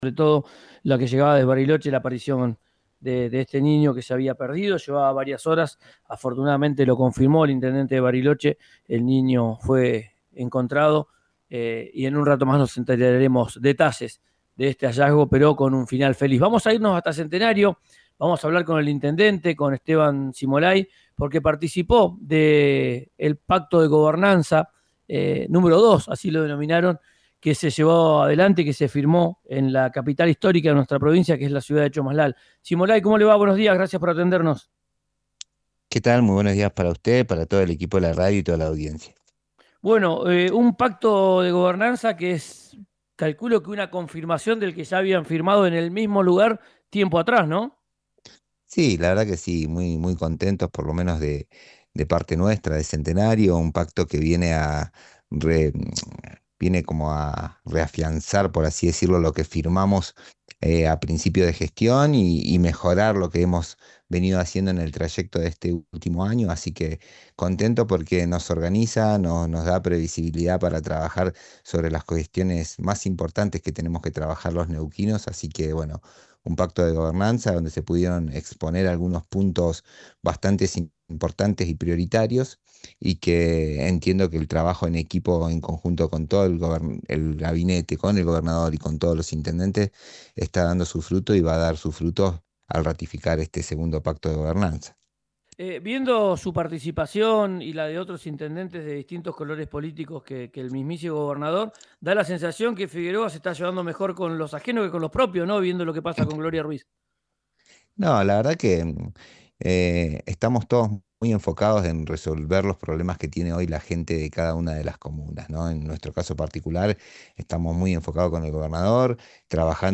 En diálogo con Ya Es Tiempo, el intendente Esteban Cimolai dijo que no vetará la ordenanza que reemplazó el nombre de presidente Néstor Kirchner por el de Doctor René Favaloro, a la vera de la ruta 7
Escuchá al intendente de Centenario, Esteban Cimolai, por RÍO NEGRO RADIO